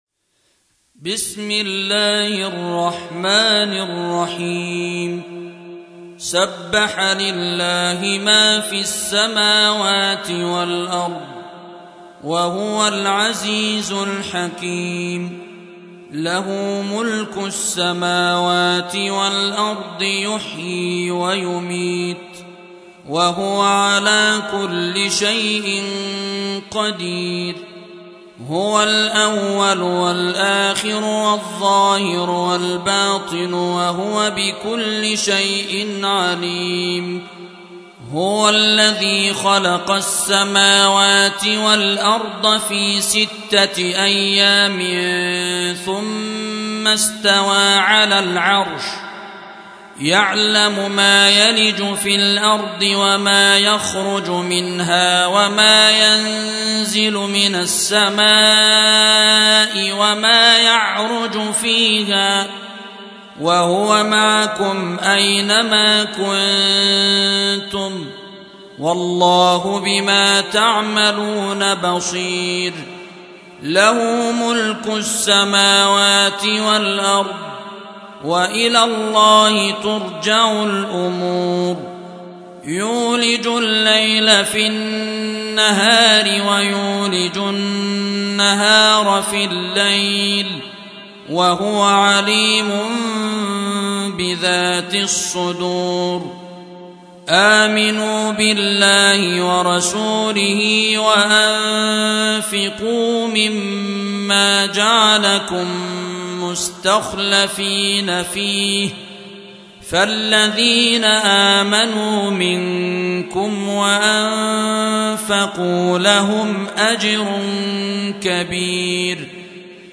57. سورة الحديد / القارئ